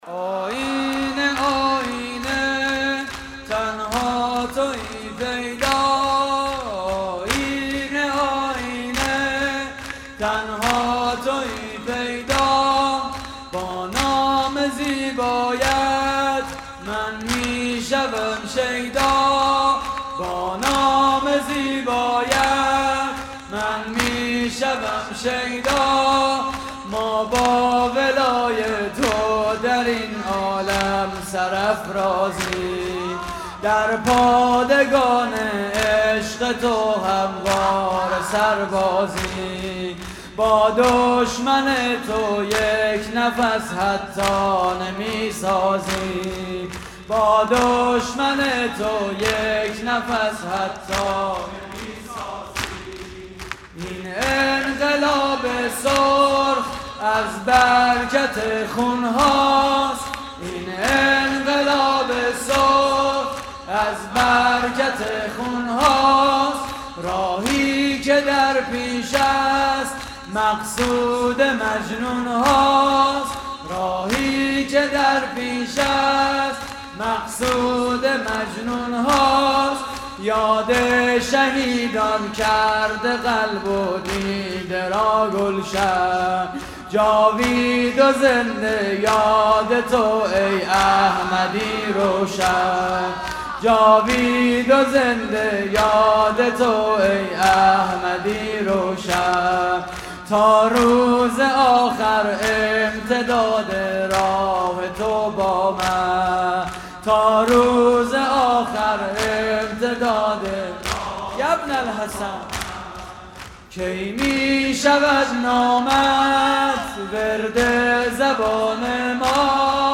شور: تنها تویی پیدا
مراسم عزاداری شب پنجم ماه محرم